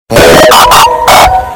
Android Notification Bass Boosted